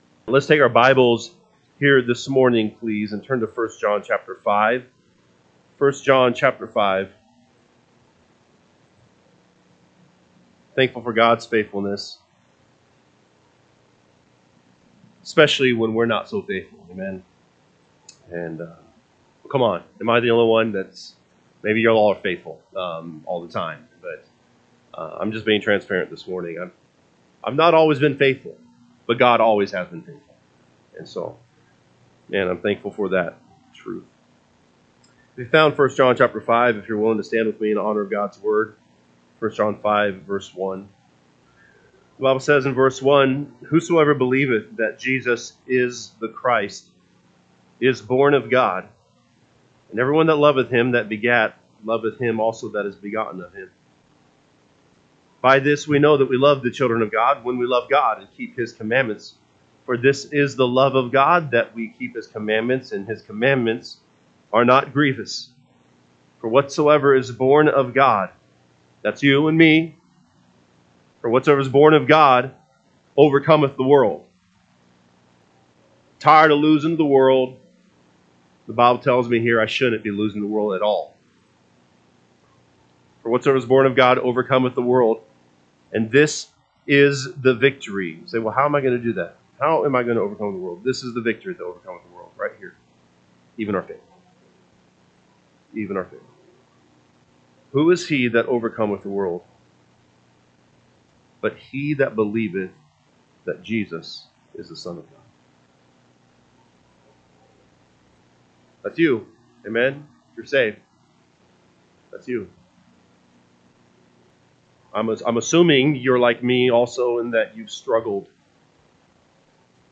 June 8, 2025 am Service 1 John 5:1-5 (KJB) 5 Whosoever believeth that Jesus is the Christ is born of God: and every one that loveth him that begat loveth him also that is begotten of him. 2&nb…